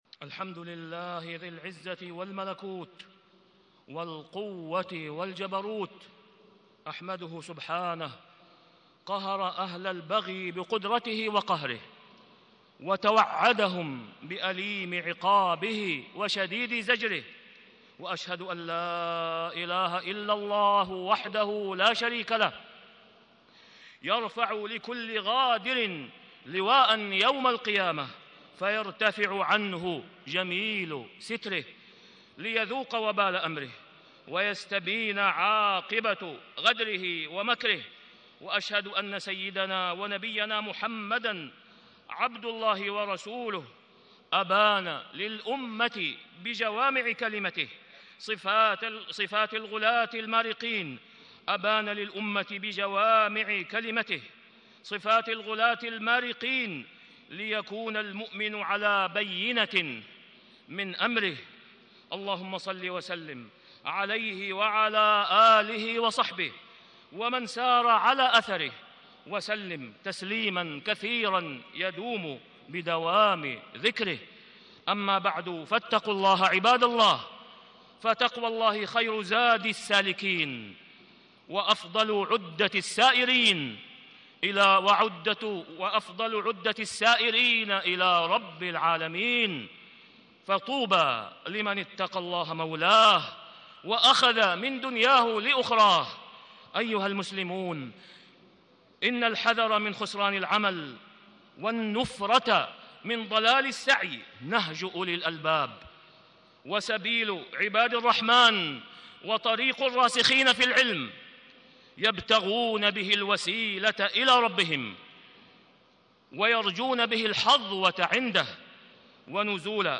تاريخ النشر ٢٢ شوال ١٤٣٦ هـ المكان: المسجد الحرام الشيخ: فضيلة الشيخ د. أسامة بن عبدالله خياط فضيلة الشيخ د. أسامة بن عبدالله خياط تحريم البغي وقتل النفس المعصومة